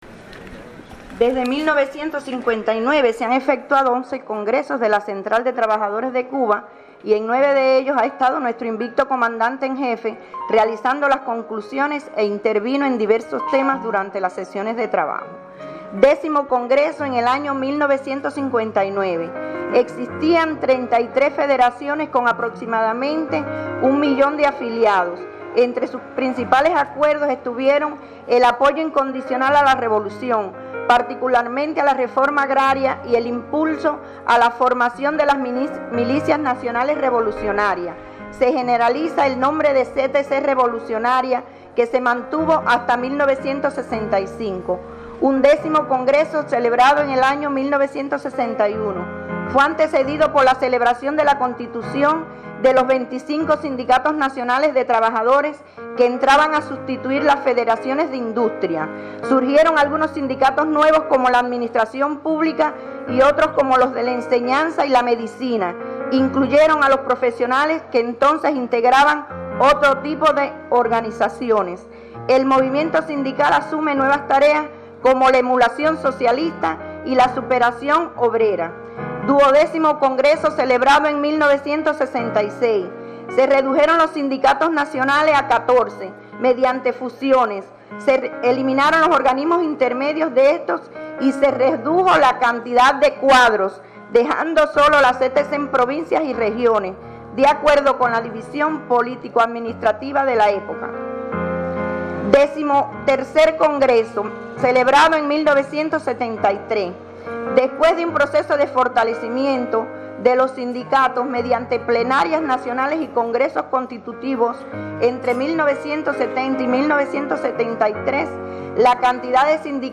La convocatoria a dar el SI por la Patria y por la nueva Constitución el próximo 24 de febrero, fue hecha en el matutino especial de la sede de la Central de Trabajadores de Cuba en Granma, por el aniversario 80 de la constitución de esa organización.